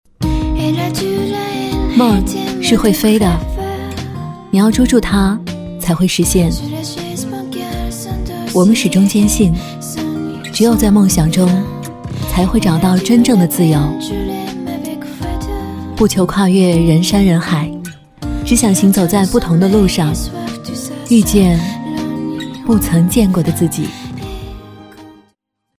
微电影配音
女国84_其他_微电影_梦清新文艺.mp3